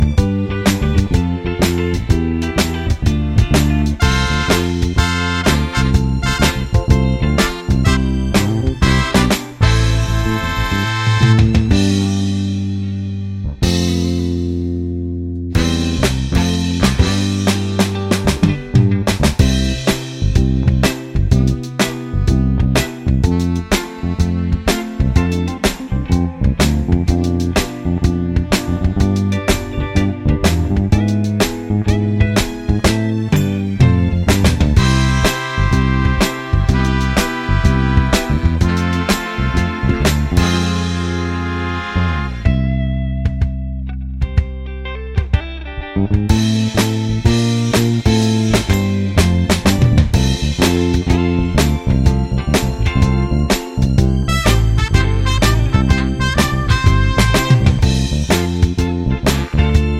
no Backing Vocals Disco 3:03 Buy £1.50